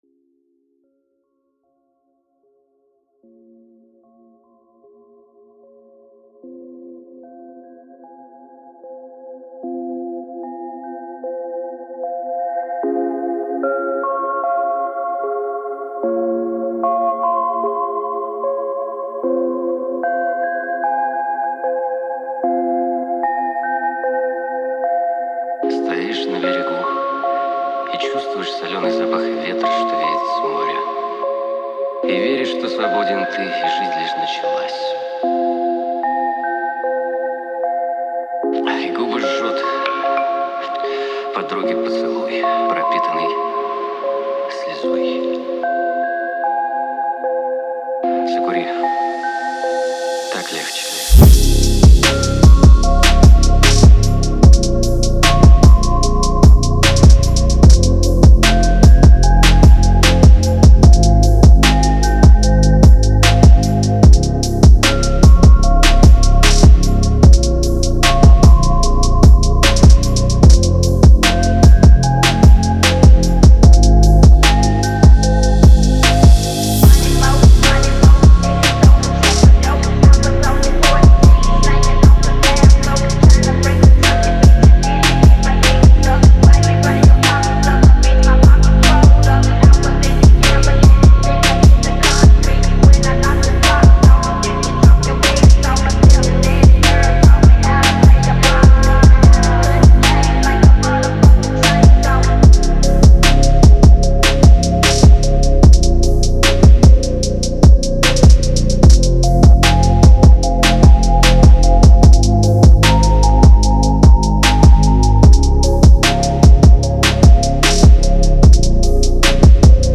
Фонк музыка
русский фонк